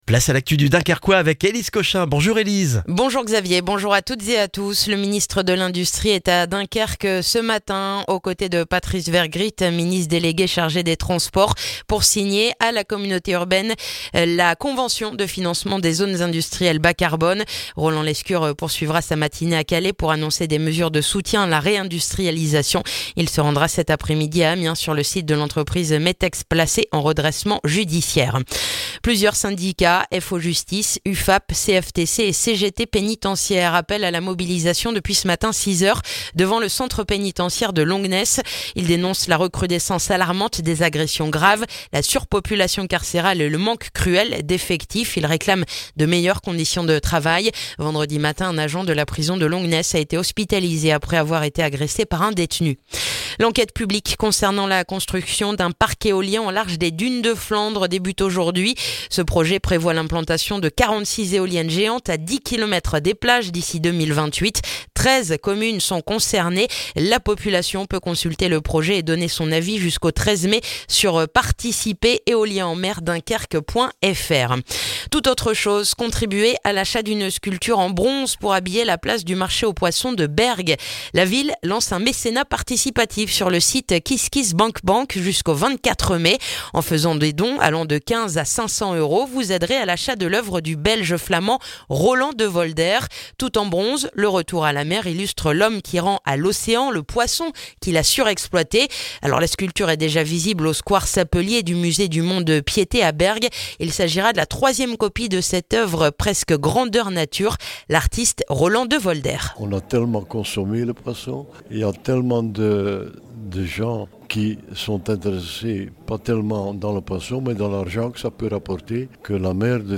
Le journal du lundi 8 avril dans le dunkerquois